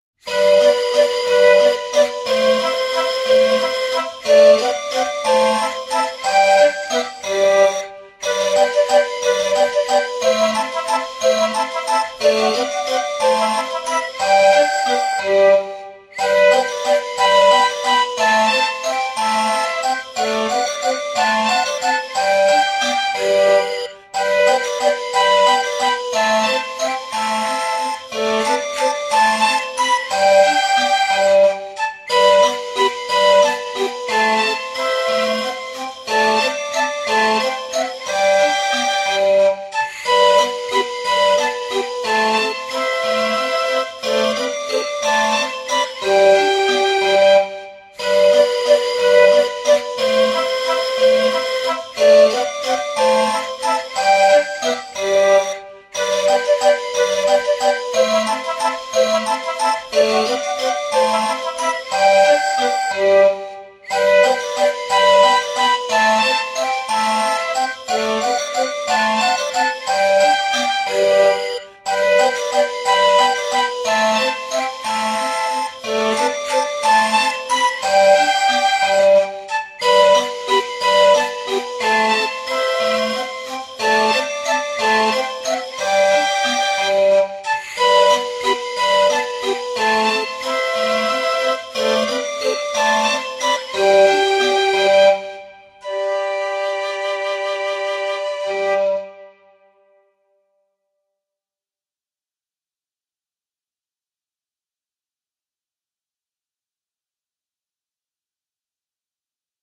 Детская сценическая музыка для катания на карусели